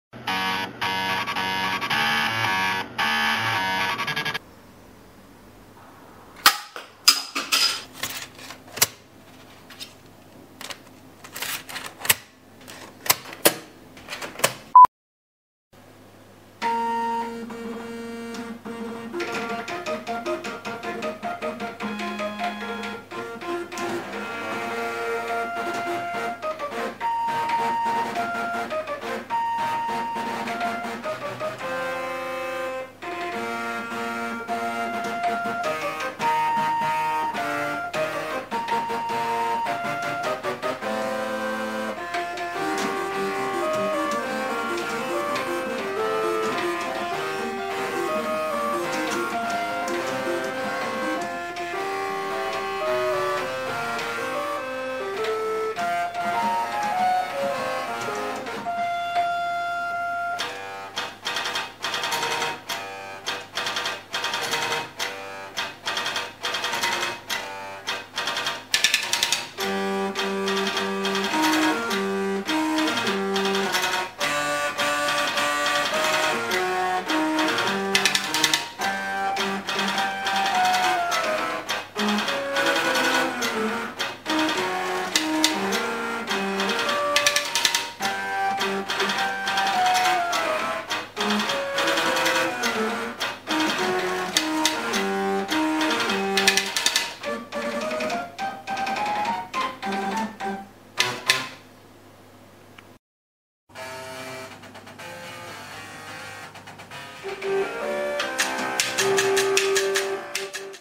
by computer hardware orchestra